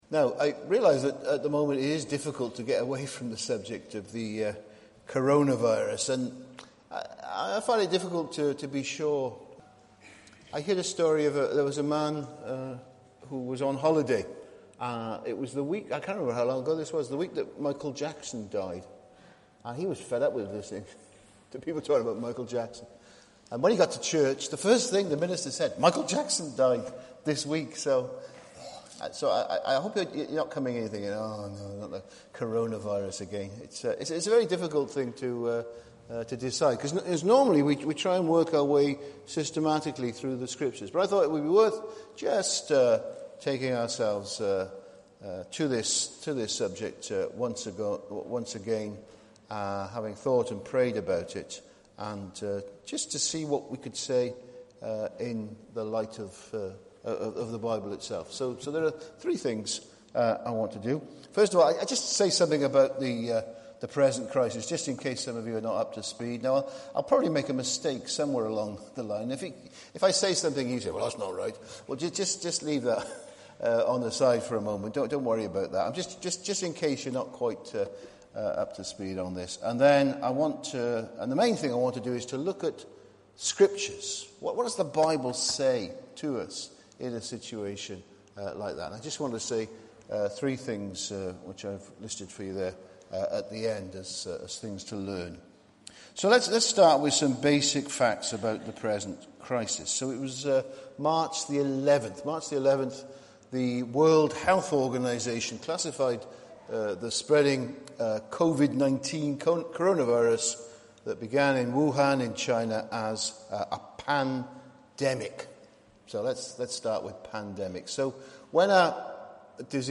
Morning service here.